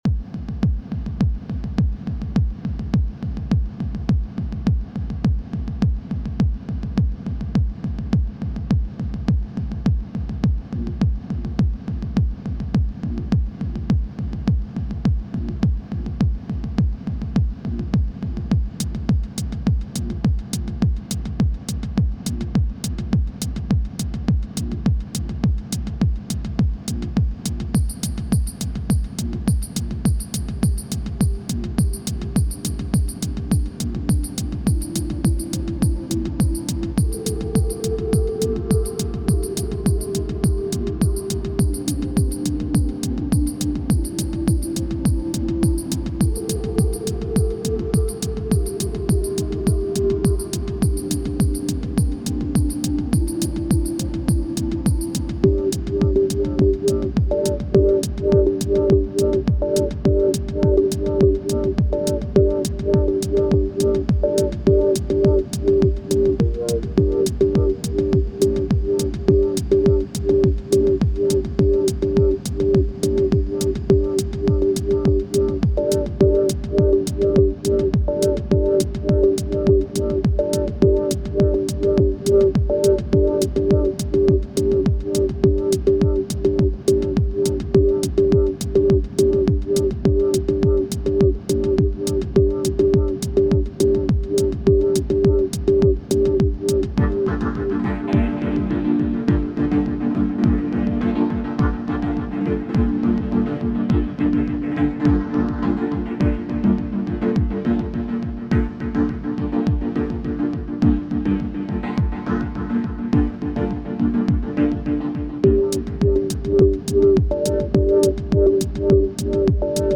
deep techno tracktrack length 3:32